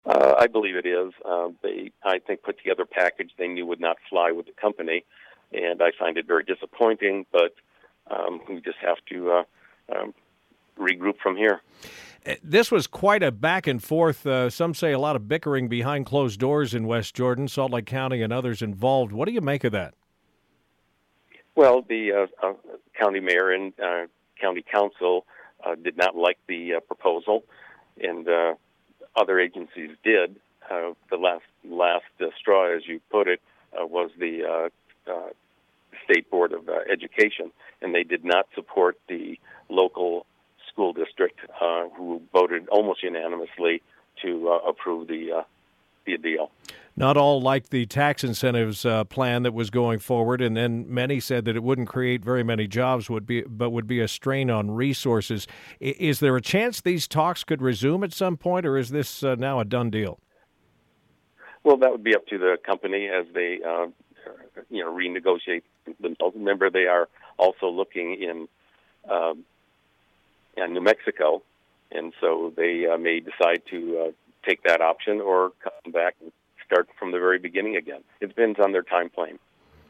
West Jordan has called off a deal to provide potential tax incentives to a top tech company to build a data center in the city. On Utah's Afternoon News, we asked city manager Mark Palesh if the the Utah Board of Education's "no" vote was the last straw.